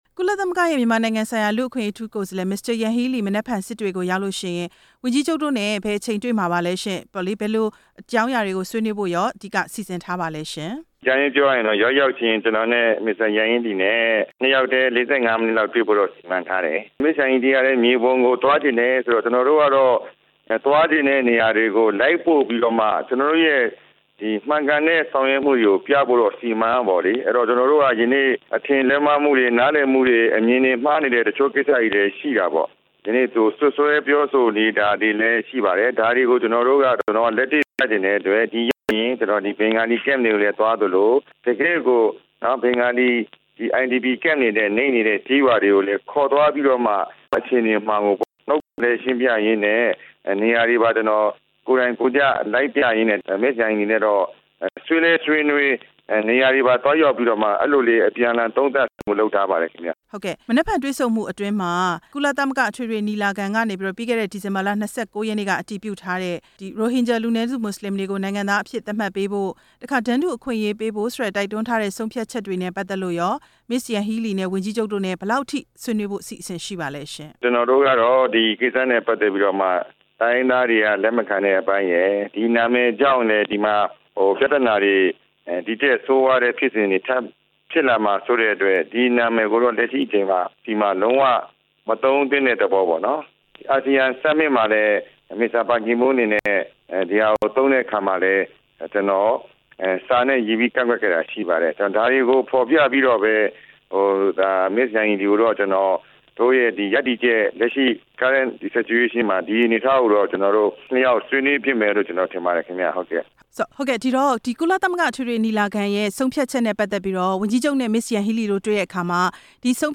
၀န်ကြီးချုပ် ဦးမောင်မောင်အုန်းကို မေးမြန်းချက်